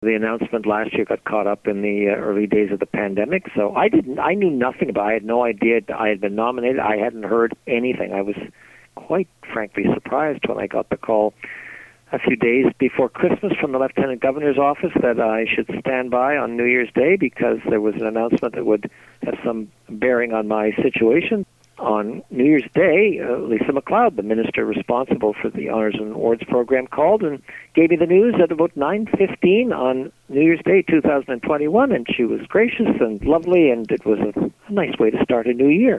Sean Conway Order of Ontario interview